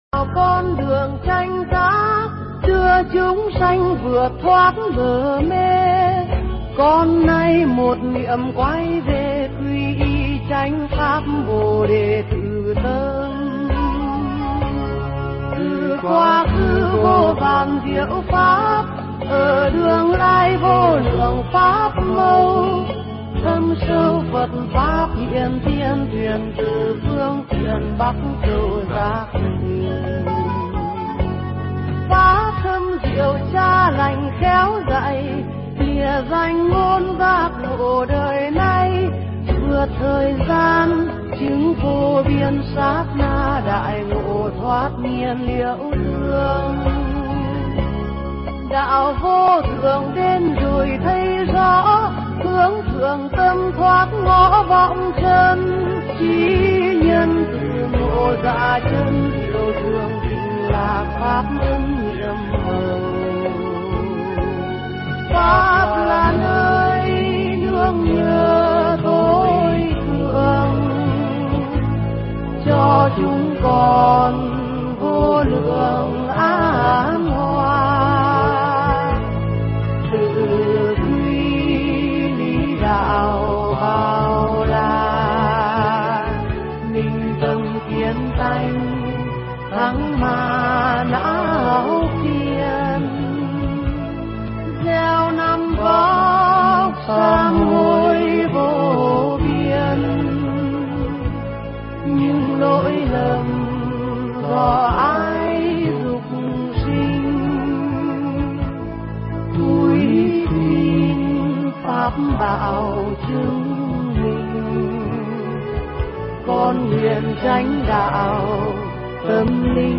Nghe Mp3 thuyết pháp Vi Diệu Pháp Nhập Môn Phần 2